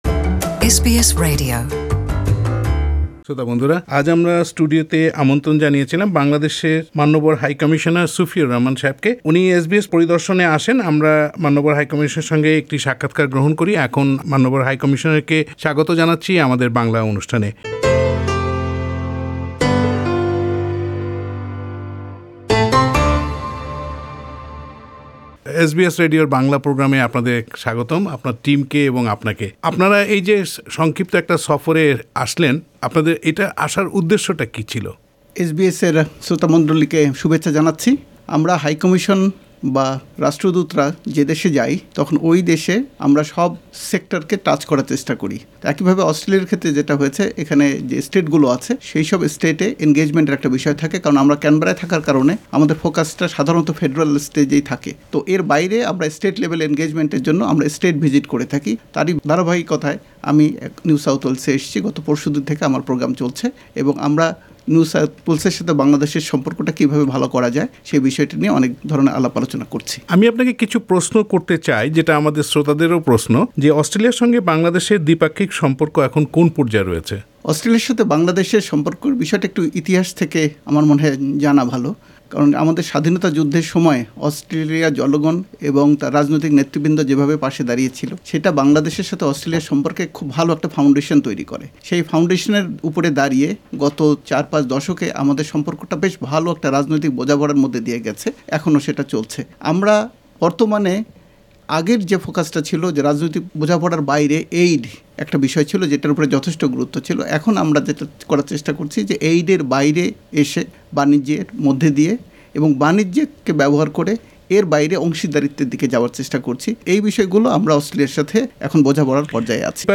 Listen to HE Mohammad Sufiur Rahman’s full interview (in Bangla) with SBS Bangla in the audio player above.